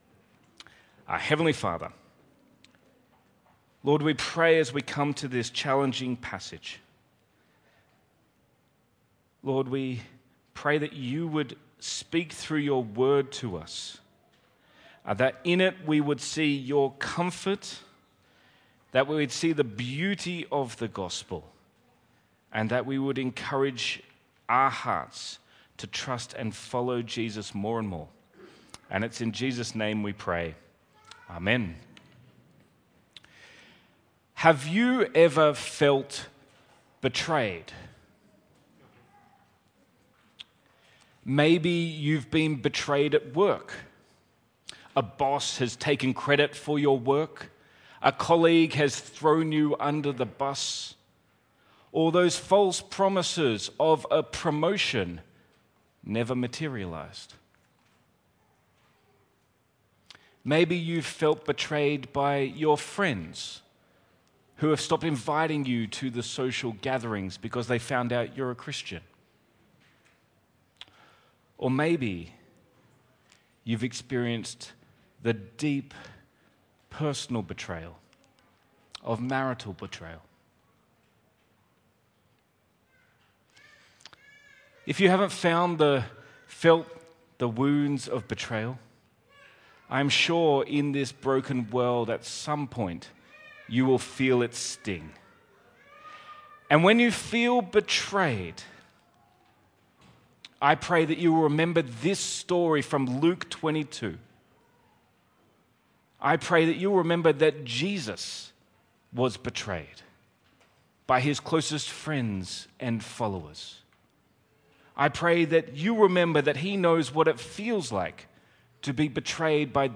Sermon Series | St Matthew's Wanniassa